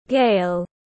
Gale /ɡeɪl/